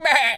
goat_baa_stressed_hurt_08.wav